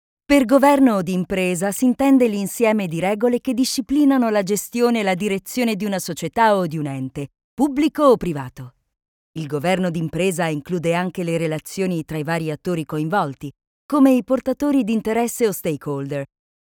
My voice is particulary clear and warm, professional and pleasant for long narrations (documentaries or audiobooks), tempting and intense for commercials.
italienisch
Sprechprobe: eLearning (Muttersprache):
elearning_1.mp3